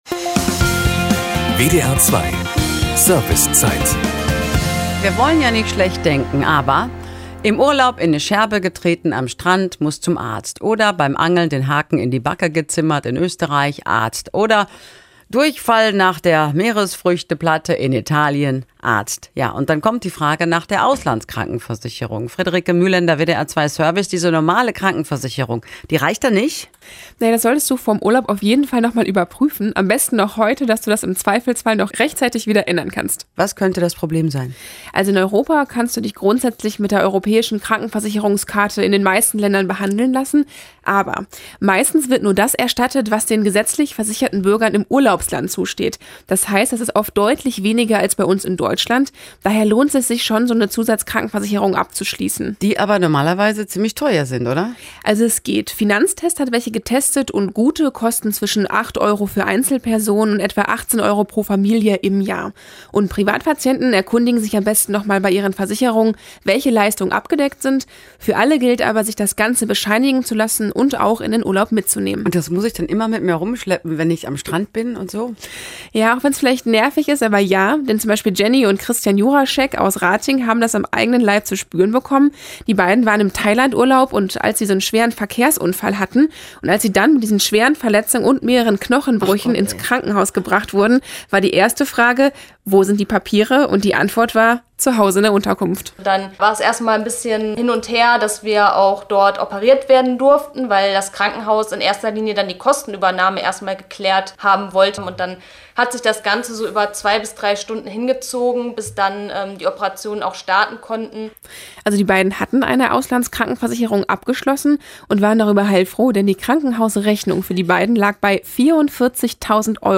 • Im Radio bei der WDR2 Servicezeit (11.07.2018): Auslandskrankenversicherung checken